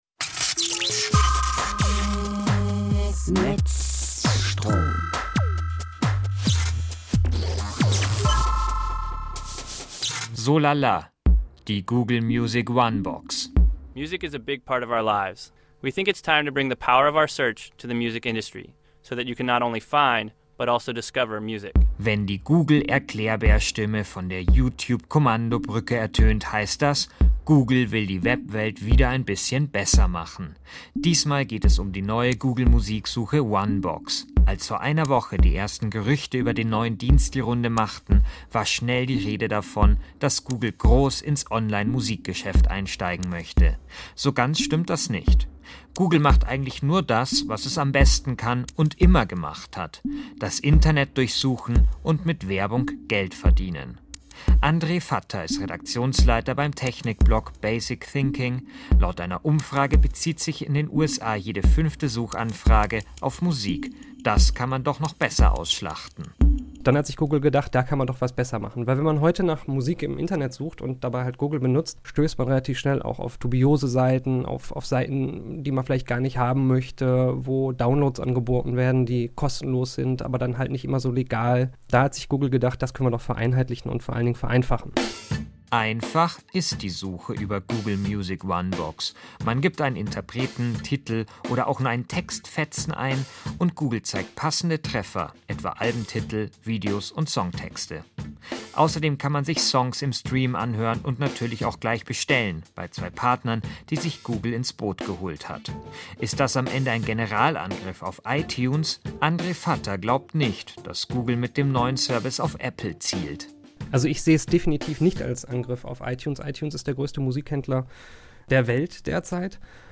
Interview mit Bayern2 / Zündfunk